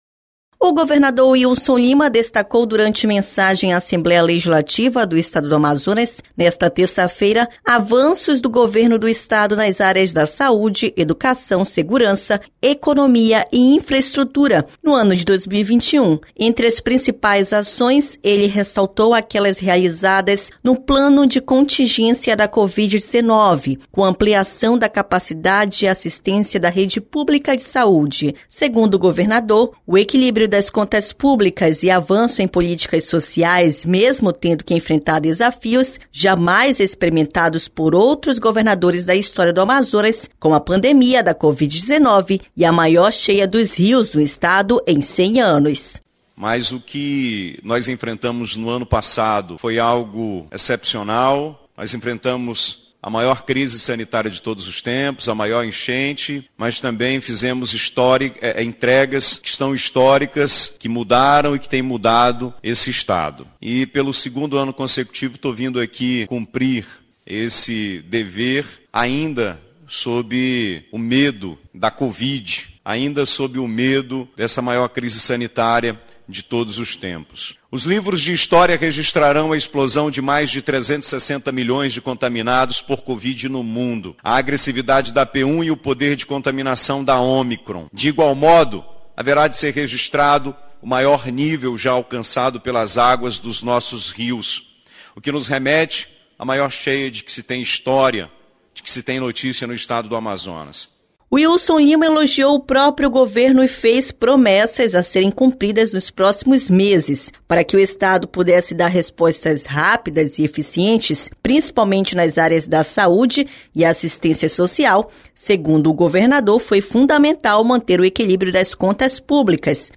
O governador Wilson Lima destacou, durante mensagem à Assembleia Legislativa do Estado do Amazonas, nesta terça-feira, avanços do Governo do Estado nas áreas da saúde, educação, segurança, economia e infraestrutura no ano de 2021.